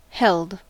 Ääntäminen
Vaihtoehtoiset kirjoitusmuodot (rikkinäinen englanti) 'eld hield Ääntäminen US Tuntematon aksentti: IPA : /ˈhɛld/ Haettu sana löytyi näillä lähdekielillä: englanti Held on sanan hold partisiipin perfekti.